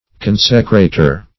Search Result for " consecrater" : The Collaborative International Dictionary of English v.0.48: Consecrater \Con"se*cra`ter\, n. Consecrator.